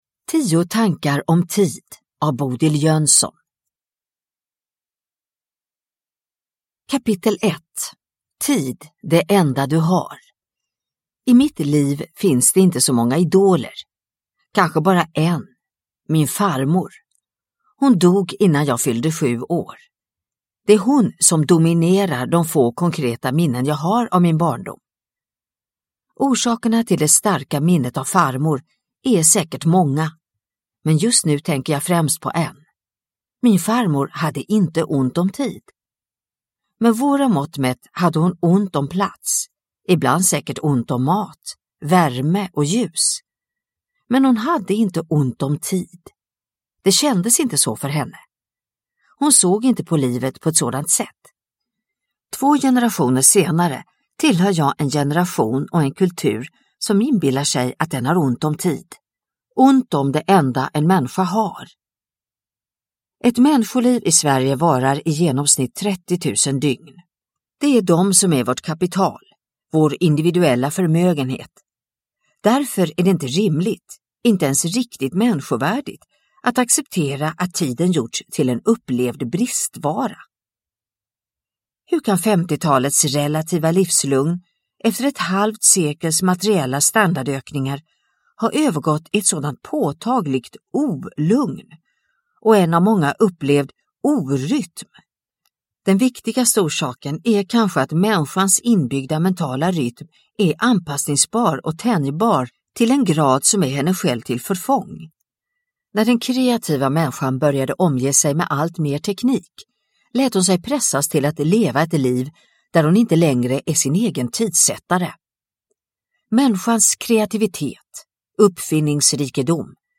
Tio tankar om tid – Ljudbok – Laddas ner